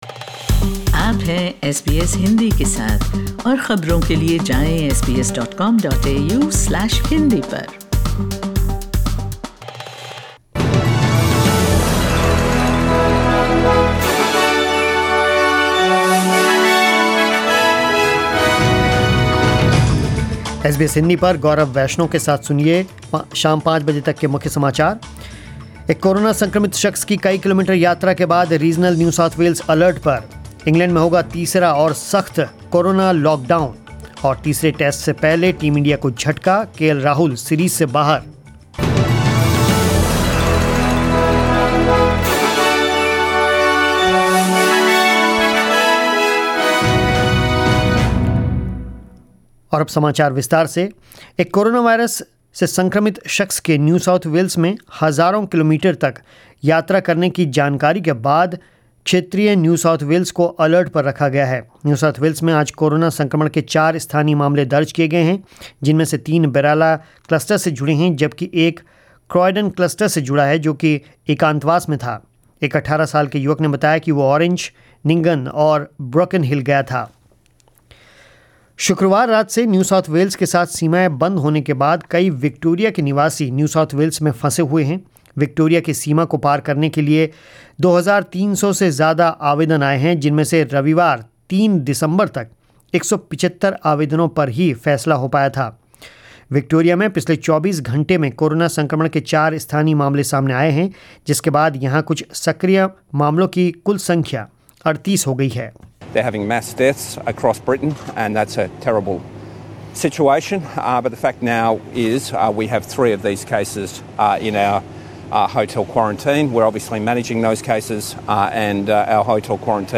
News in Hindi 05 January 2021 ** Regional New South Wales on high alert after an coronavirus-infected man travelled through// ** India sees lowest daily COVID tally since June, 58 mutant virus cases.